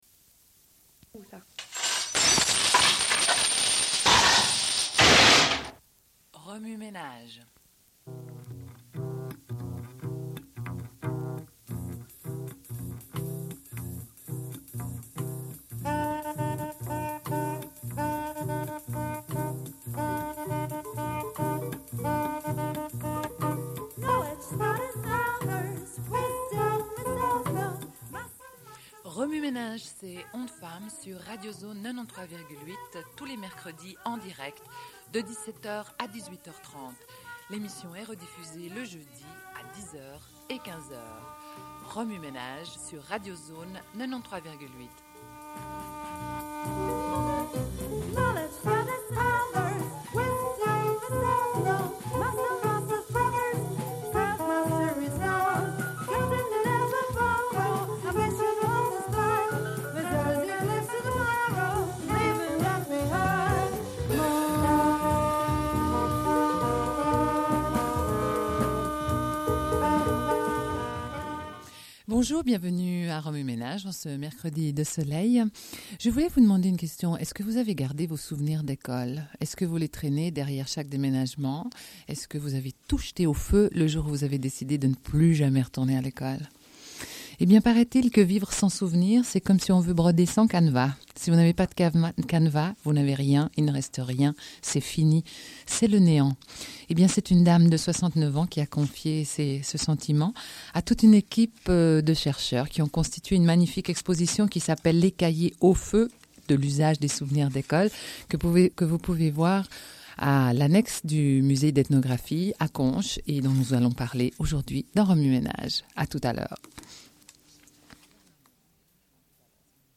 Une cassette audio, face A00:31:24